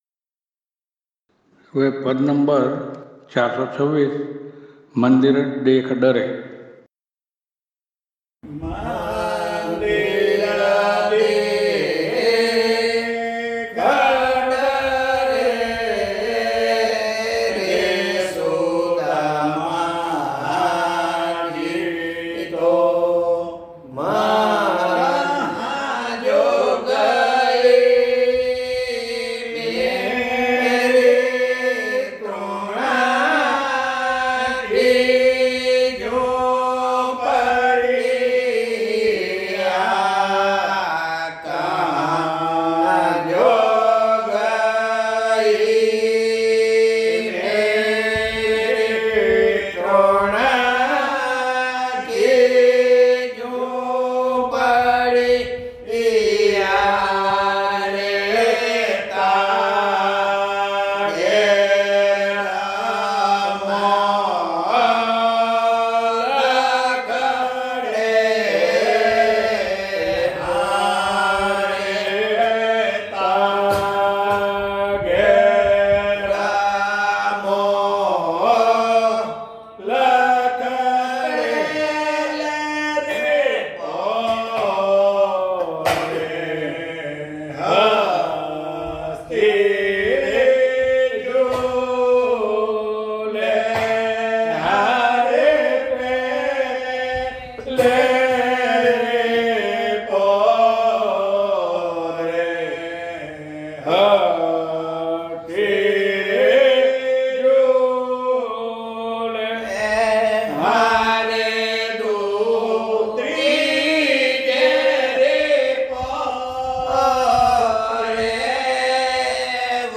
નાદબ્રહ્મ પદ - ૪૨૬, રાગ - સારંગNādbrahma pada 426, rāga - sārang